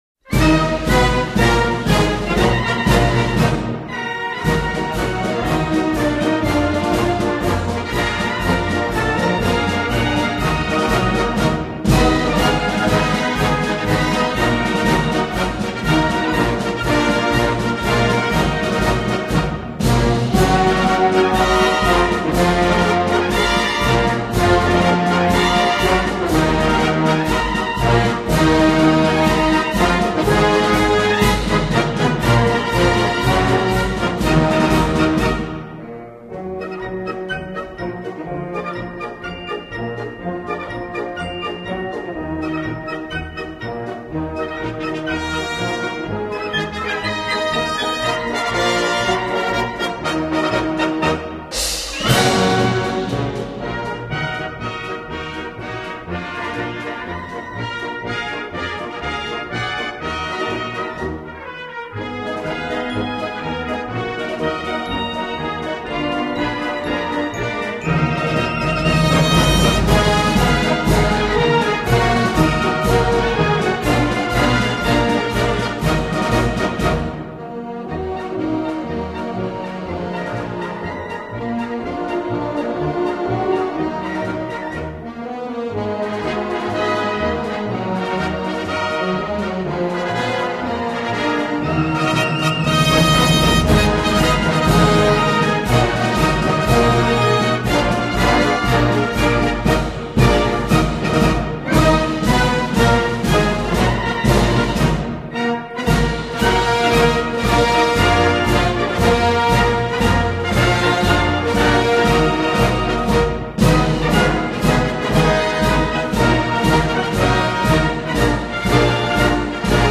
описание:   «Прощание славянки» — русский патриотический марш композитора и дирижёра Василия Ивановича Агапкина.